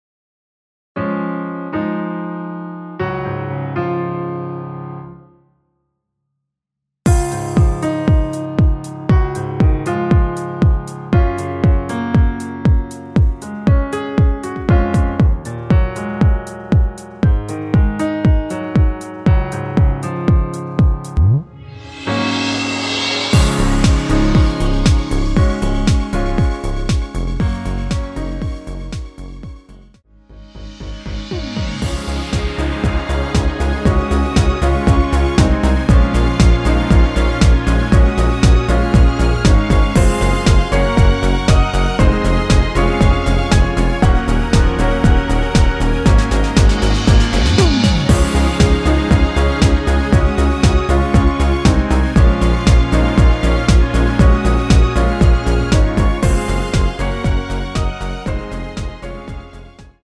랩 부분 삭제한(-1) MR입니다.
전주가 없는 곡이라 2마디 전주 만들어 놓았습니다.
랩부분과 마지막 랩부분은 후렴부분도 없습니다.
Db
◈ 곡명 옆 (-1)은 반음 내림, (+1)은 반음 올림 입니다.
앞부분30초, 뒷부분30초씩 편집해서 올려 드리고 있습니다.